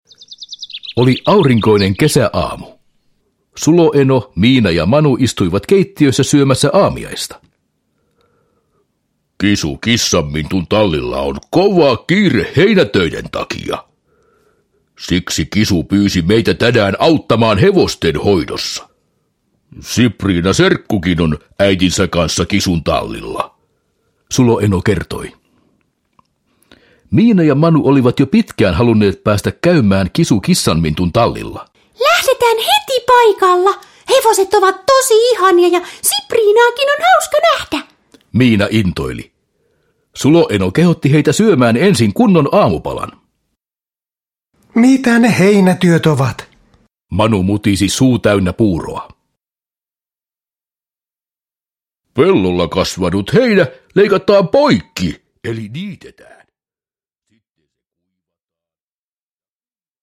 Miina ja Manu tallilla – Ljudbok – Laddas ner